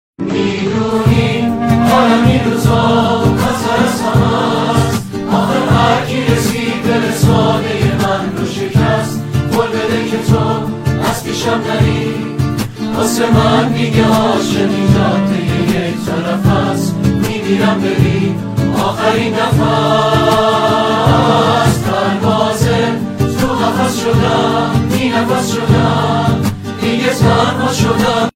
اجرای گروهی